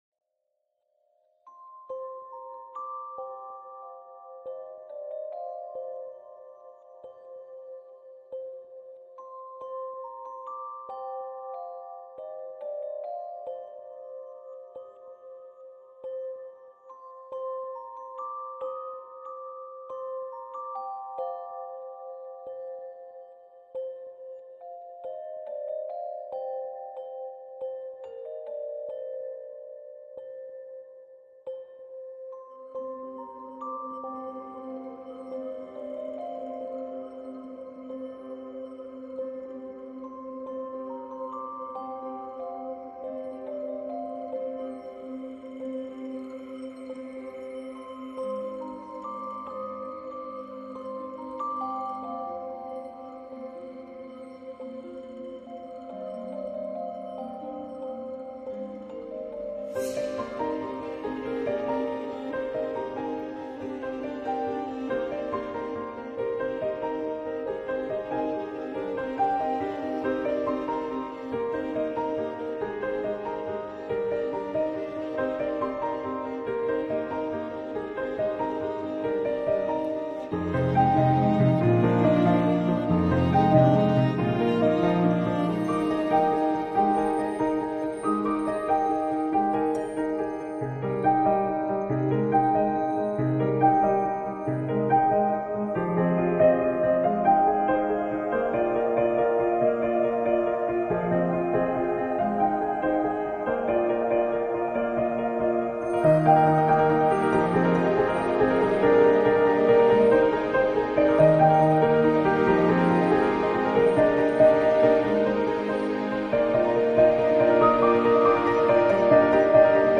موسیقی بیکلام
پیانو